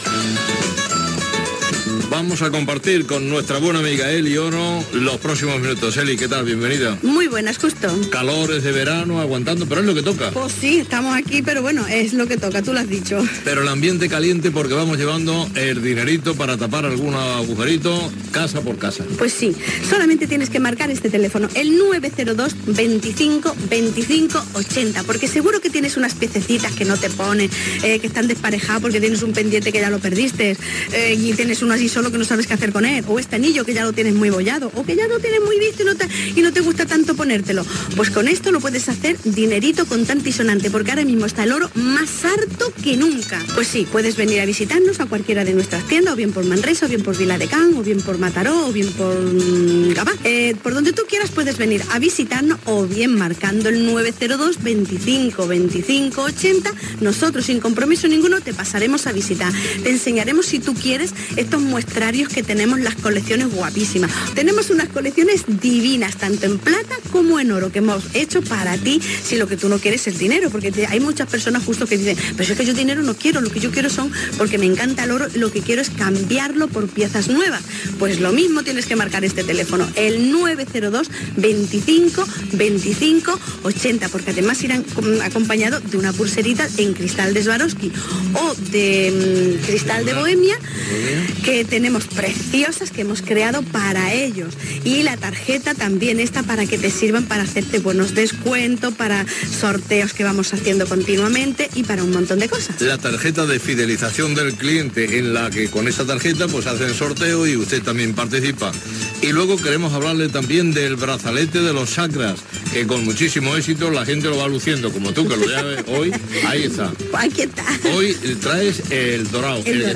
Espai publicitari d'Eli Oro
Info-entreteniment
FM